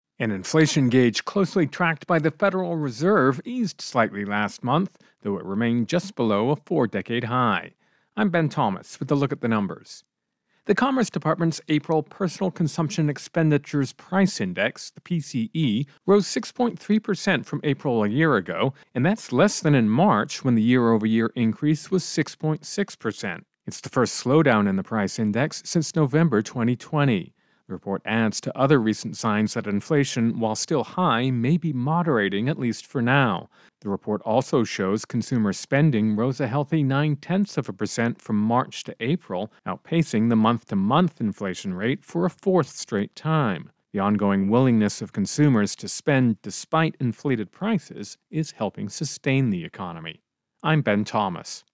Intro and voicer "Consumer Spending"